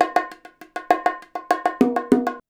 100BONG09.wav